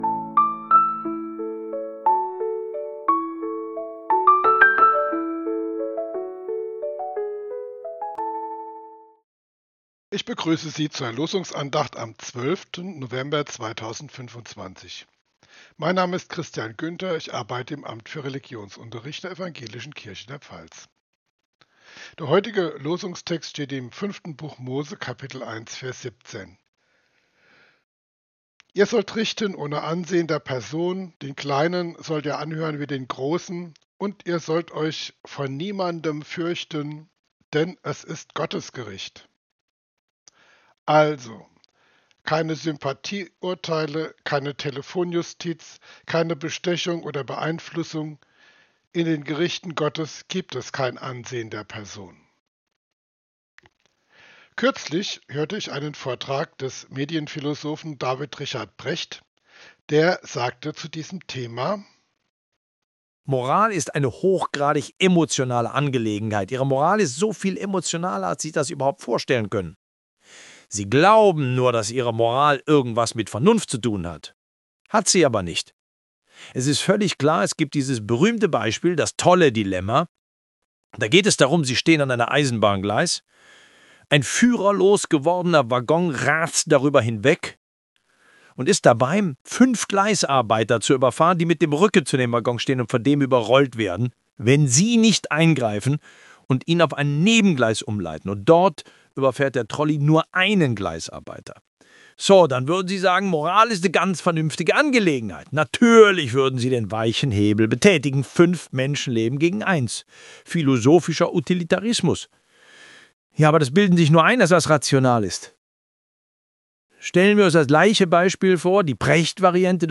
Losungsandachten
Die Stimme aus dem Zitat von D.R.Precht ist KI-generiert.